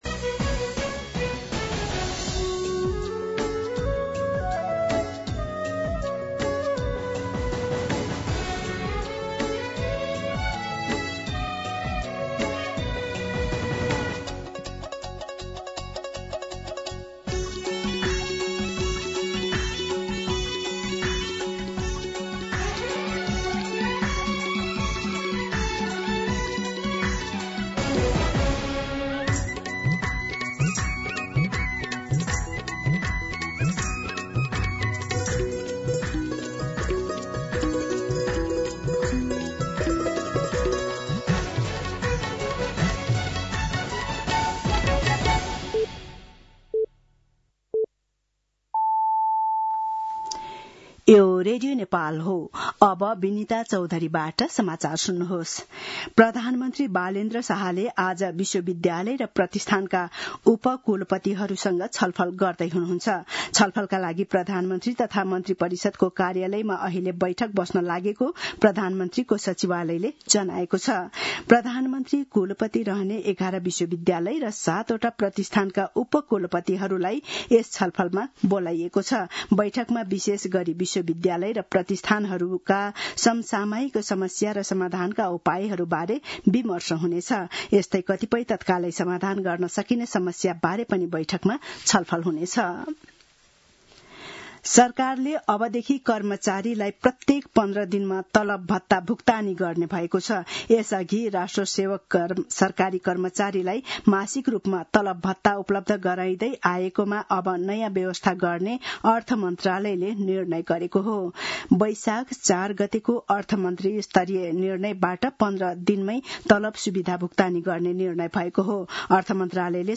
An online outlet of Nepal's national radio broadcaster
मध्यान्ह १२ बजेको नेपाली समाचार : ७ वैशाख , २०८३